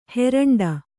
♪ heraṇḍa